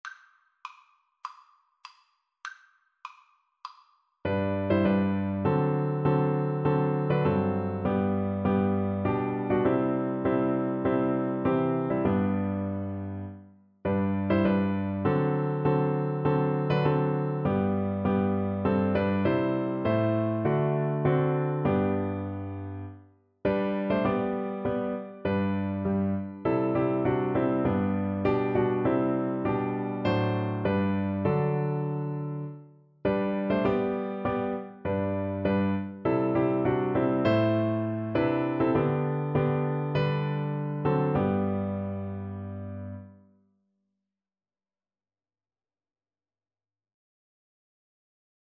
4/4 (View more 4/4 Music)
Classical (View more Classical Flute Music)